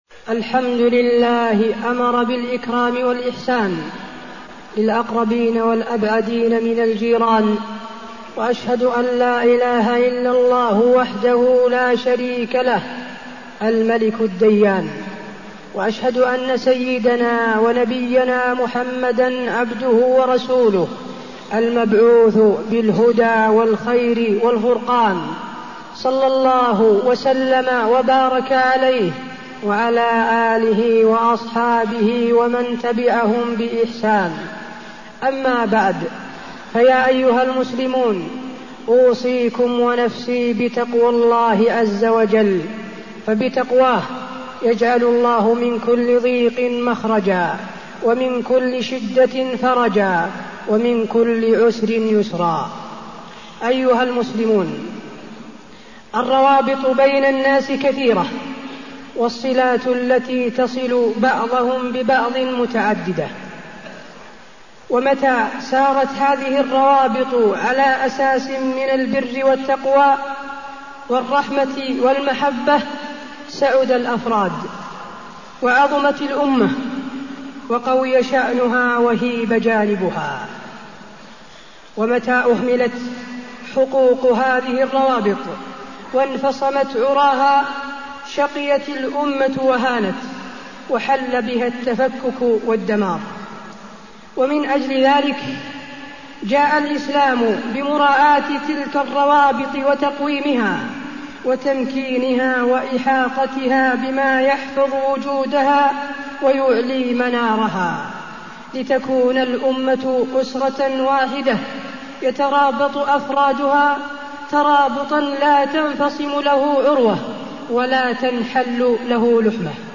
تاريخ النشر ٢٧ رجب ١٤٢٠ هـ المكان: المسجد النبوي الشيخ: فضيلة الشيخ د. حسين بن عبدالعزيز آل الشيخ فضيلة الشيخ د. حسين بن عبدالعزيز آل الشيخ حقوق الجار